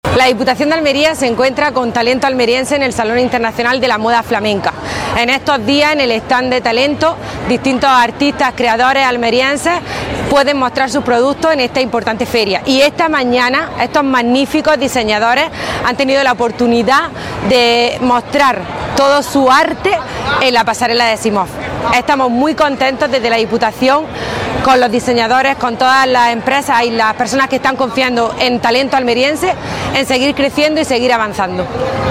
AUDIO-DIPUTADA-SIMOF-ALMERIA.mp3